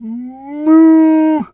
cow_2_moo_02.wav